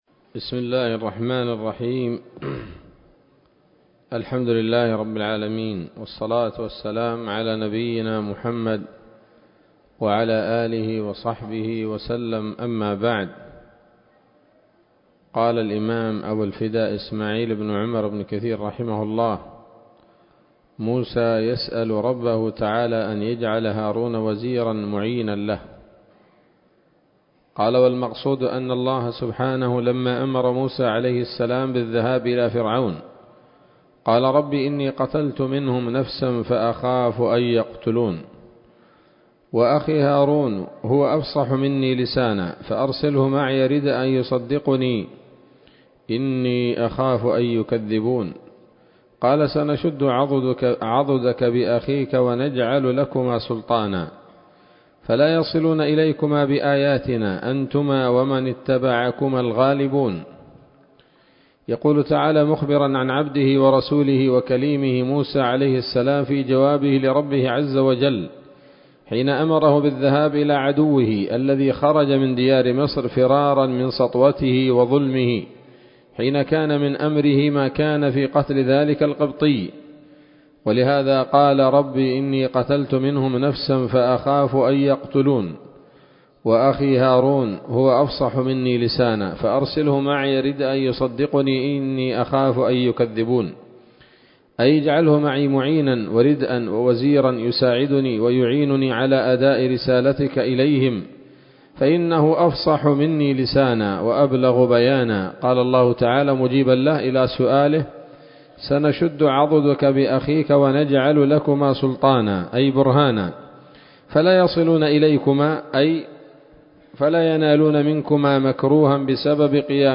الدرس السادس والثمانون من قصص الأنبياء لابن كثير رحمه الله تعالى